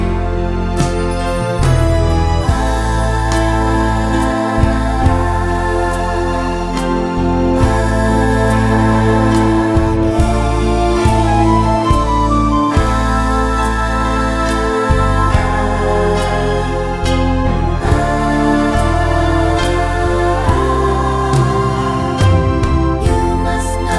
No Backing Vocals Crooners 2:35 Buy £1.50